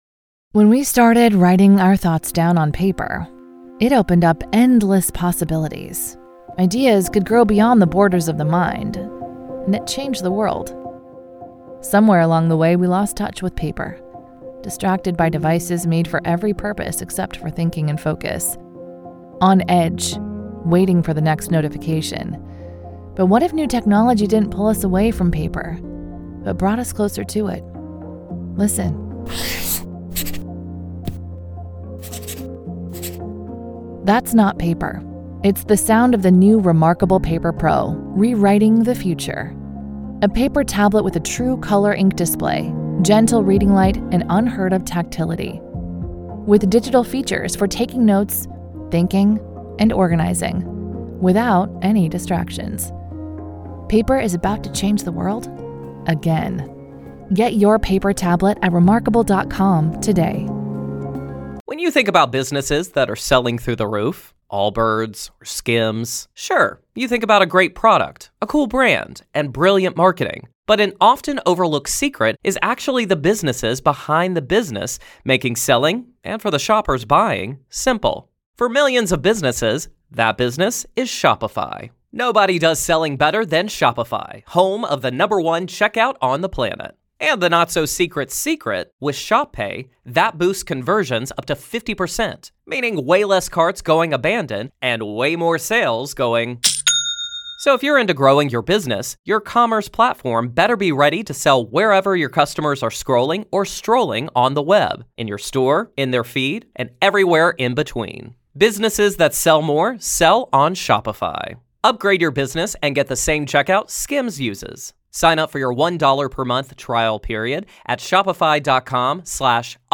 This is a daily EXTRA from The Grave Talks. Grave Confessions is an extra daily dose of true paranormal ghost stories told by the people who survived them!